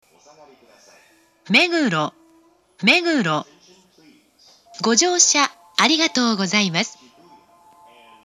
１番線到着放送
meguro1bansen-totyaku4.mp3